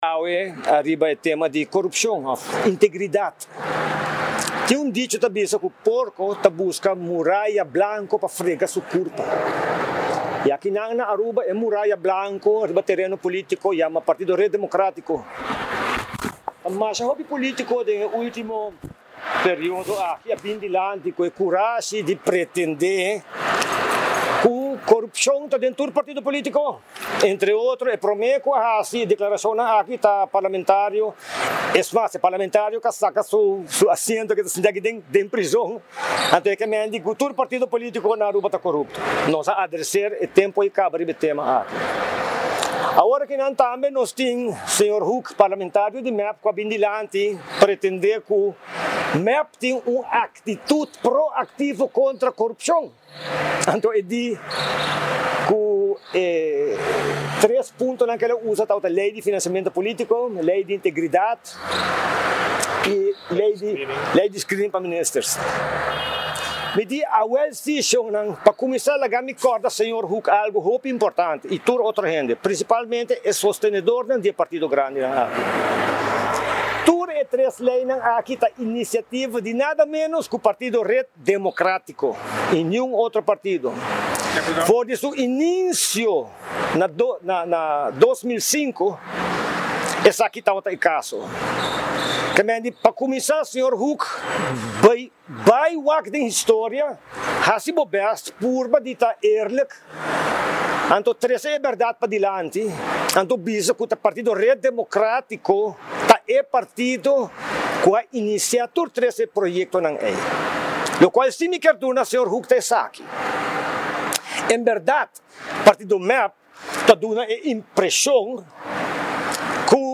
Partido RED a yama un conferencia di prensa , Ricardo Croes di RED a elabora riba integridad. Segun Croes tin un dicho ta bisa “ porco ta busca muraya blanco pa frega su curpa” Segun Croes e muraya blanco ey den arena politico ta partido RED.